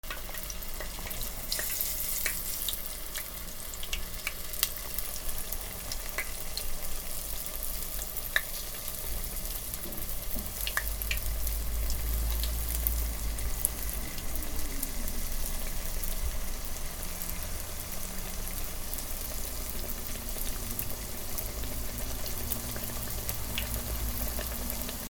なべ 揚げ物
『パチパチ』